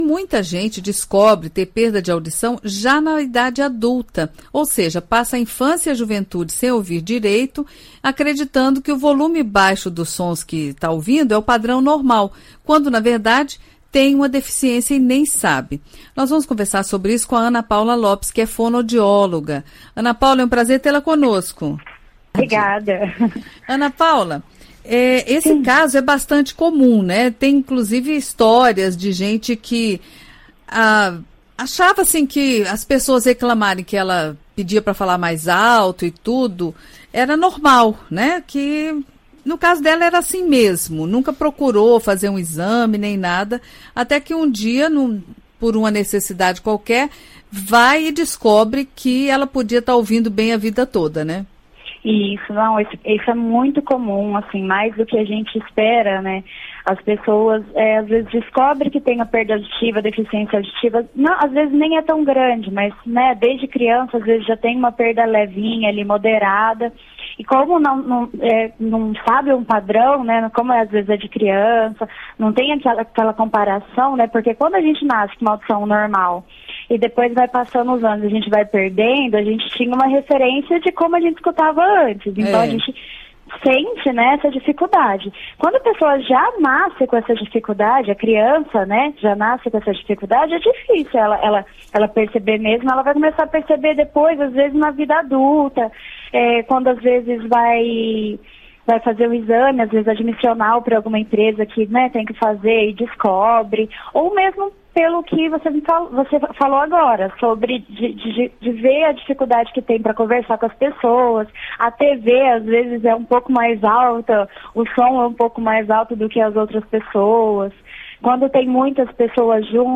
Entrevista: Saiba como como evitar a surdez